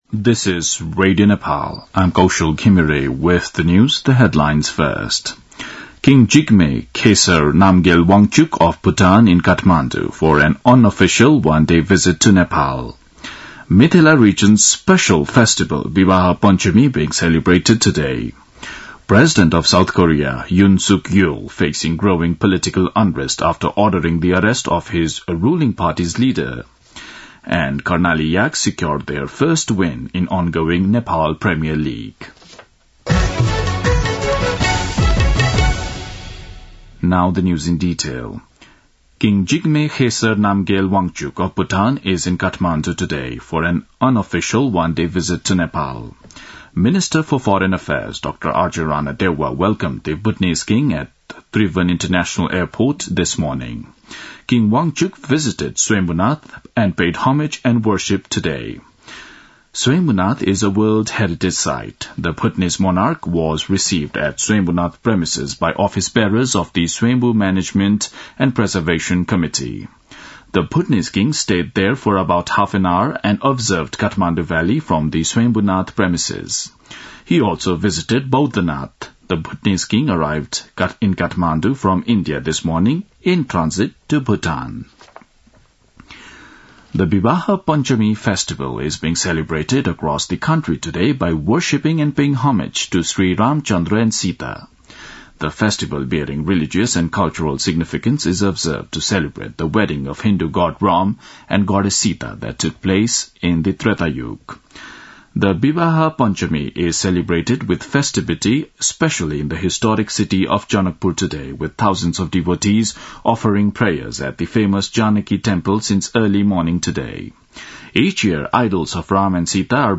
दिउँसो २ बजेको अङ्ग्रेजी समाचार : २२ मंसिर , २०८१
2-pm-english-news-1-5.mp3